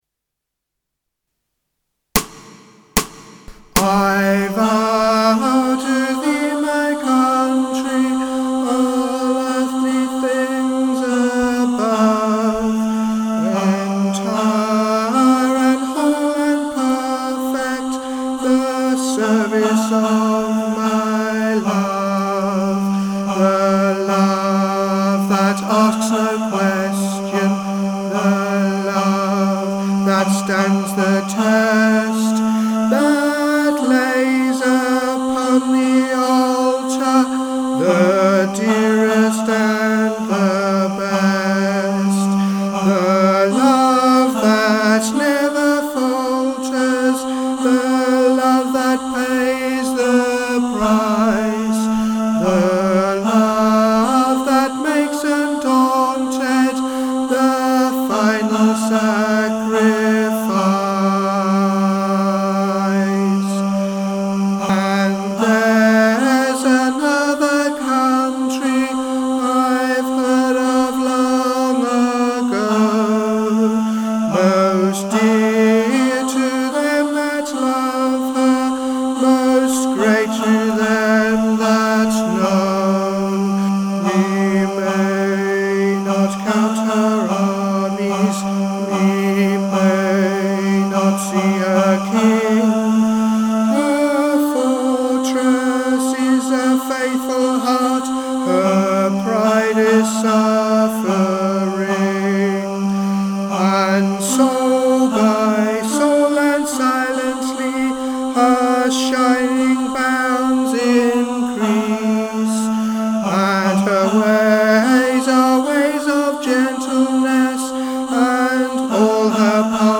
I Vow (Tenor Vocals) | Ipswich Hospital Community Choir
I-Vow-Tenor.mp3